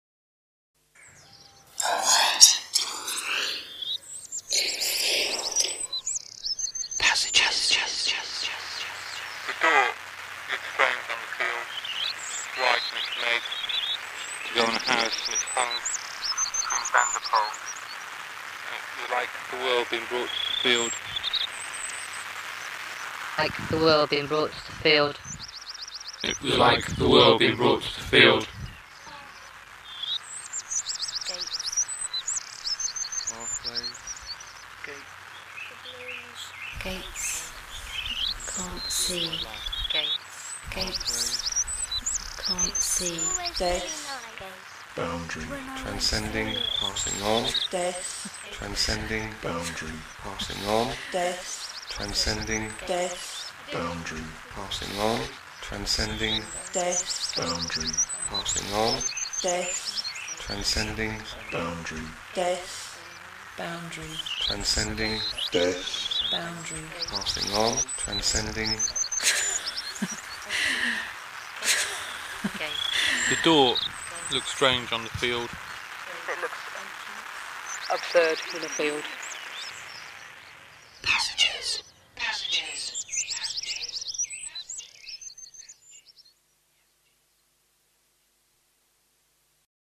based on recordings made at Passages 2003 Summer Camp.